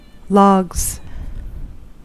Ääntäminen
Ääntäminen US : IPA : [ˈlɑɡz] Tuntematon aksentti: IPA : /ˈlɒɡz/ Haettu sana löytyi näillä lähdekielillä: englanti Käännöksiä ei löytynyt valitulle kohdekielelle.